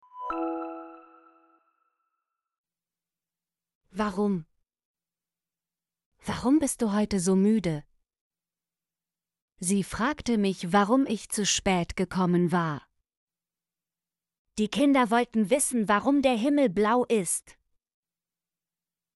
warum - Example Sentences & Pronunciation, German Frequency List